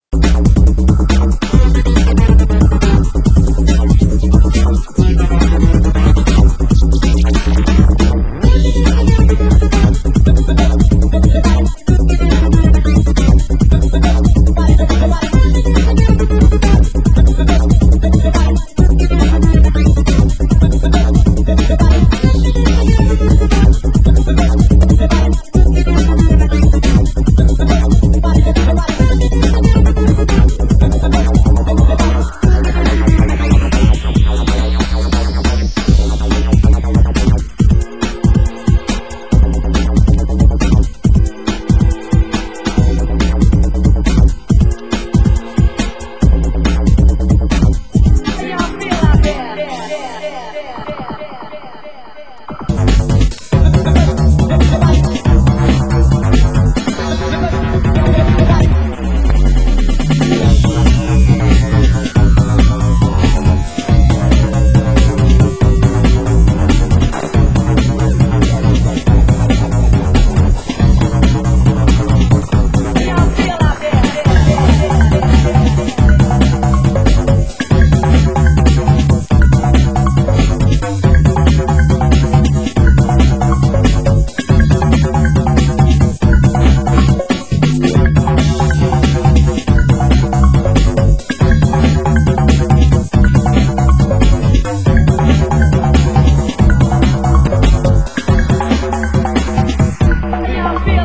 Genre: Nu Skool Breaks